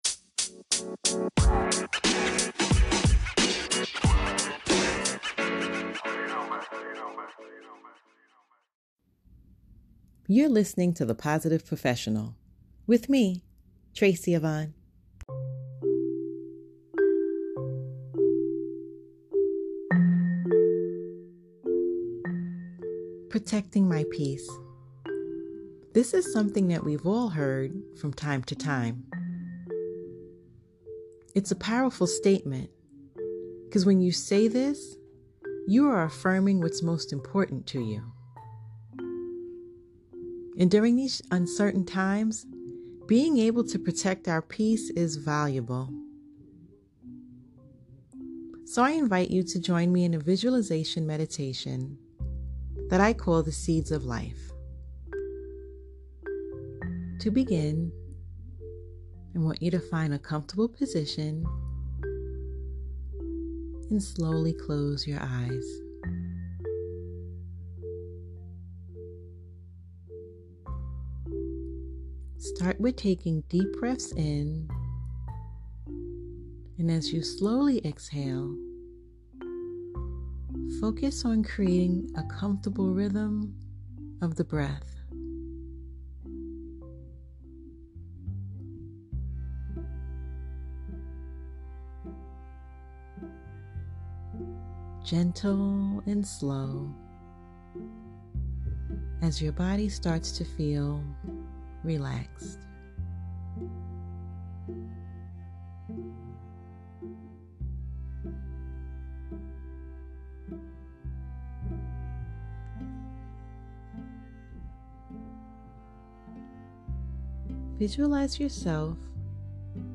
"Seeds of Life" Guided Meditaton.